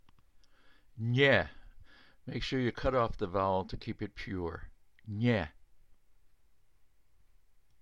Polish Words -- Baritone Voice
nie (NYEH)
NOTE: The Polish /E/ is a pure vowel, pronounced like the [E] in "met".